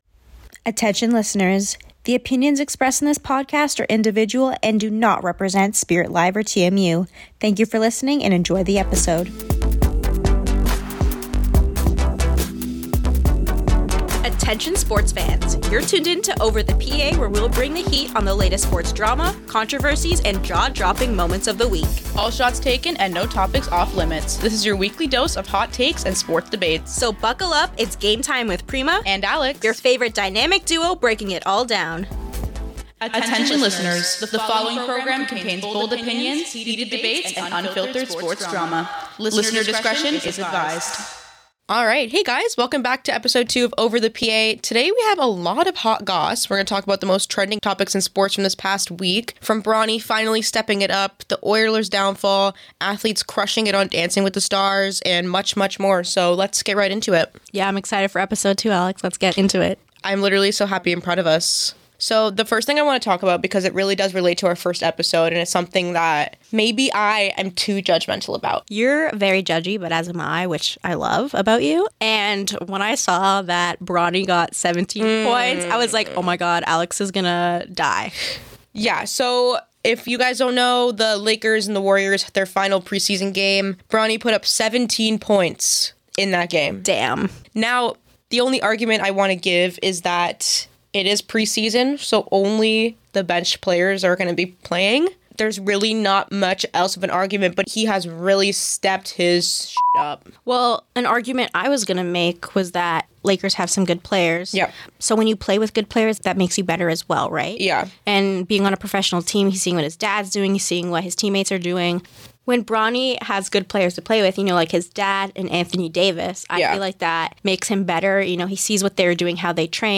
They explore some serious and not so serious topics, all pertaining to the world of sports. From athletes on Dancing with the Stars to Ferrari’s podium wins; in this episode expect plenty of laughs and unfiltered takes with this dynamic duo.